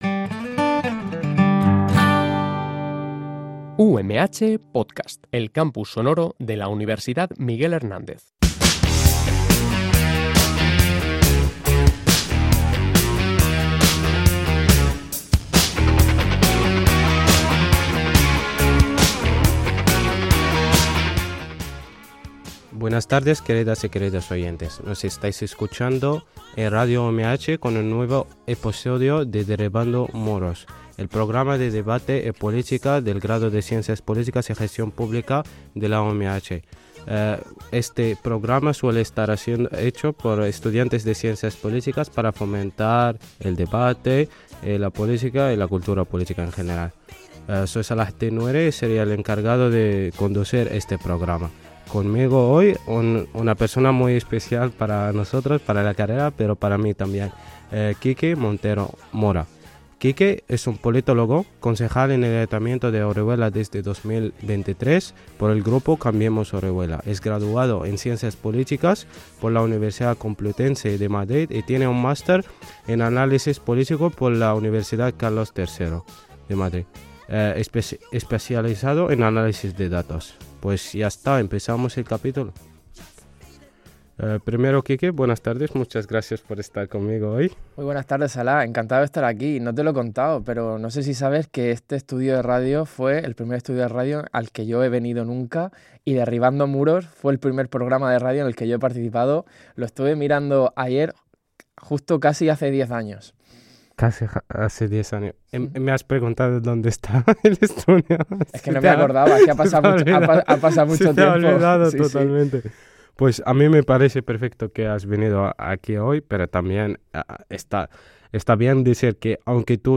(Español) Entrevista a Quique Montero Mora, Politólogo y Concejal del Ayuntamiento de Orihuela, en “Derribando Muros”, 30 de junio de 2025″